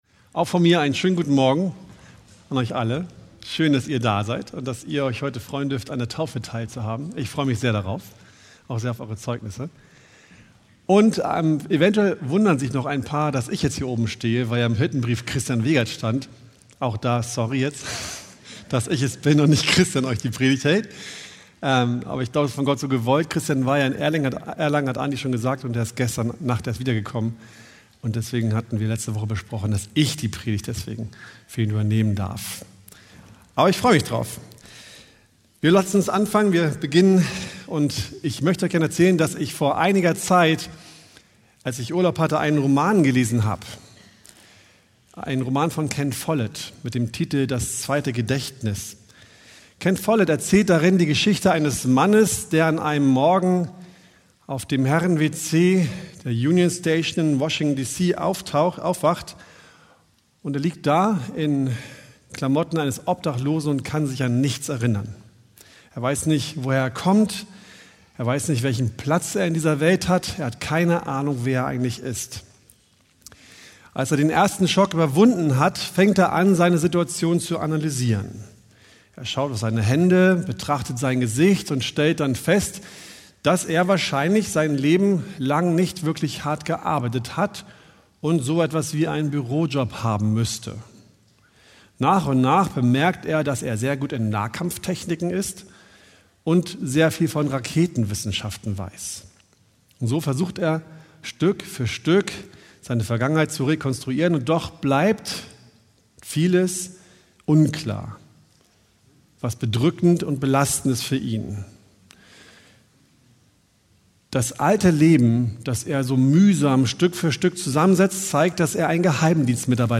Predigttext: 2. Korinther 5,17